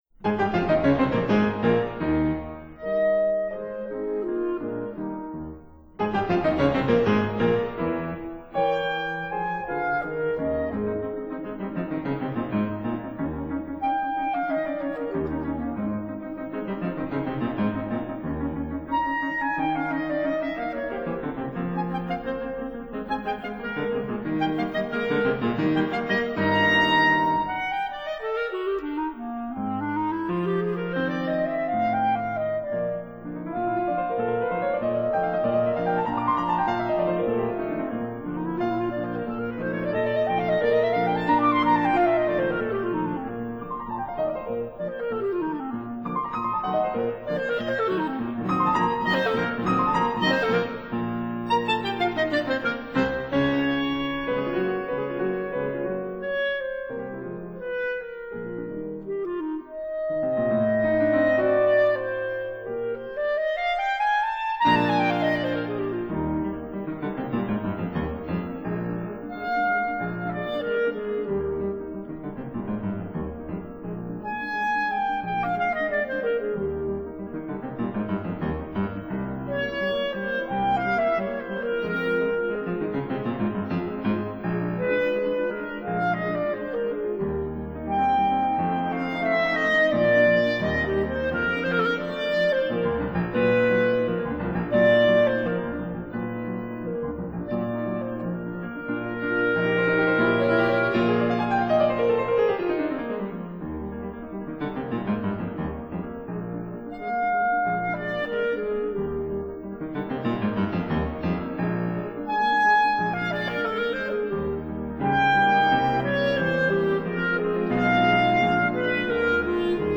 clarinet
piano